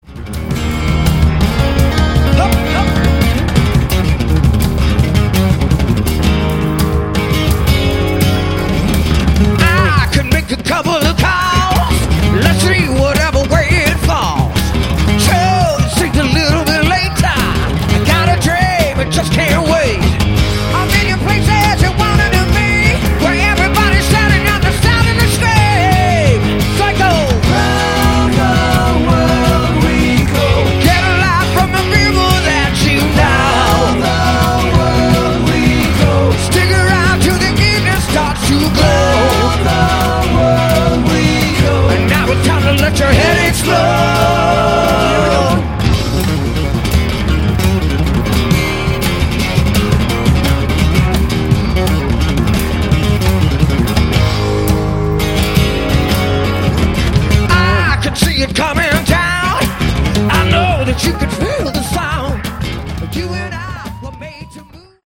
Category: Melodic Rock
lead vocals
drums, percussion, backing vocals
bass guitar, backing vocals
guitar, backing vocals